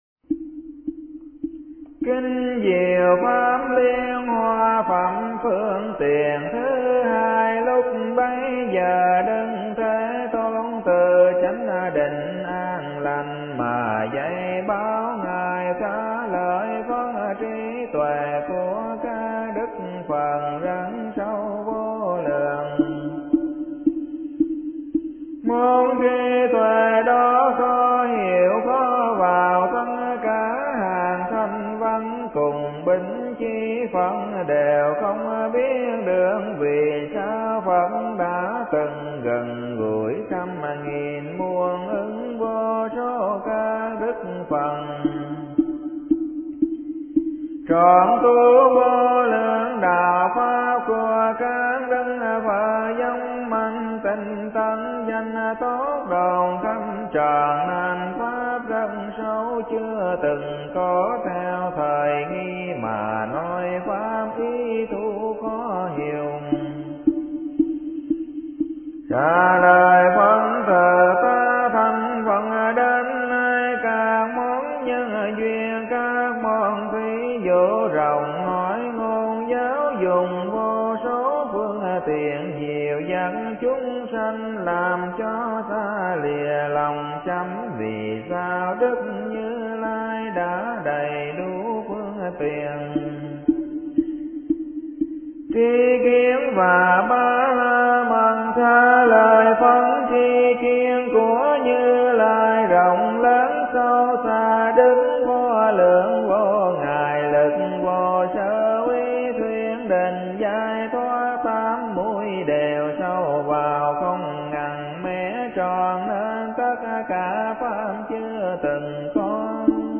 Kinh-Tung-Kinh-Phap-Hoa-02-Pham-Phuong-Tien-Thu-Hai.mp3